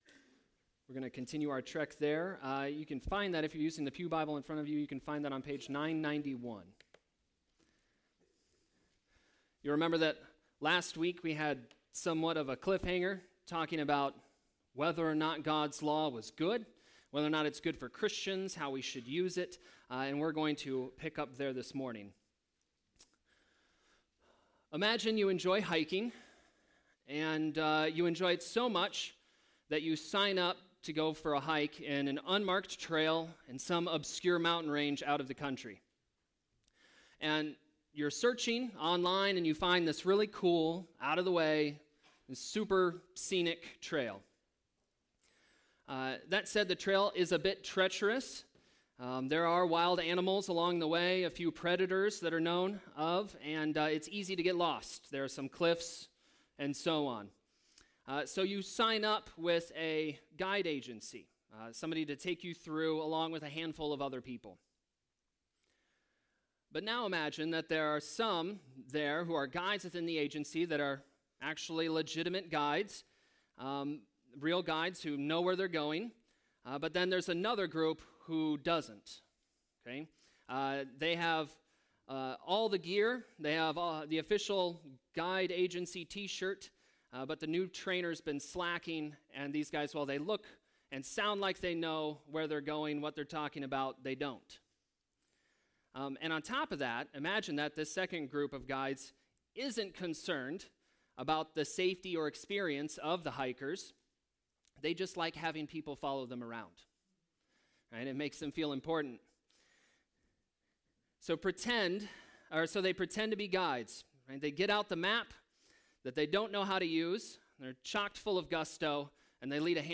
Sermon preached Sunday, January 26, 2020